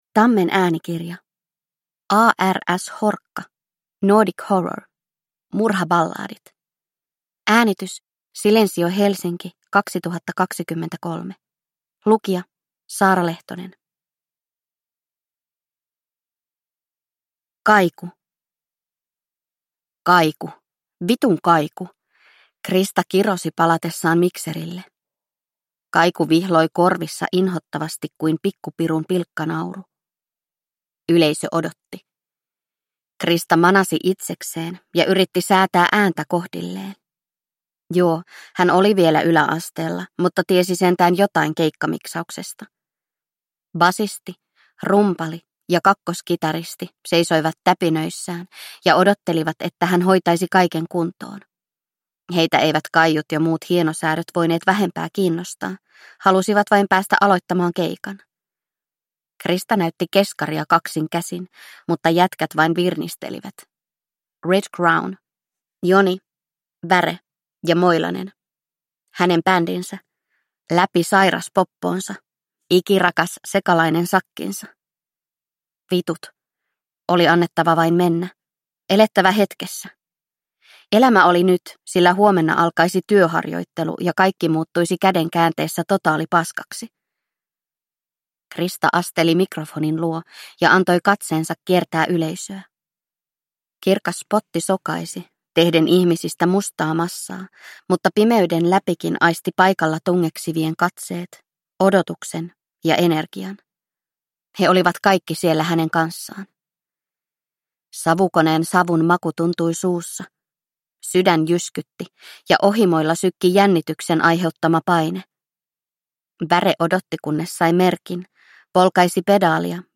Murhaballadit – Ljudbok